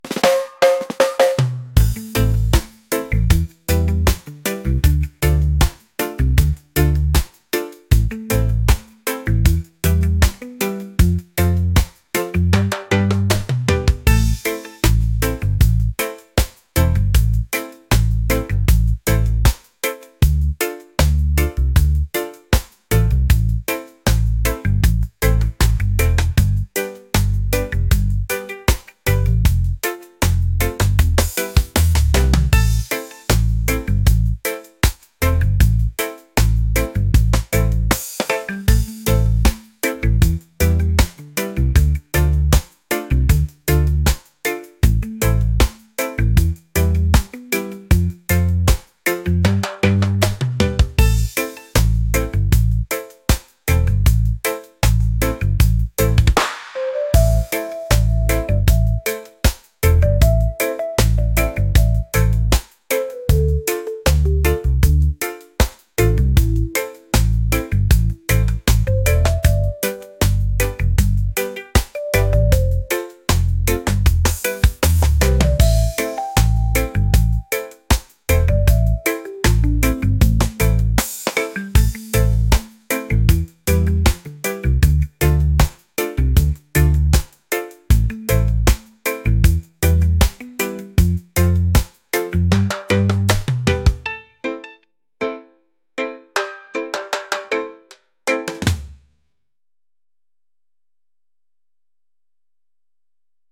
reggae | laid-back | rhythmic